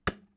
arrow.wav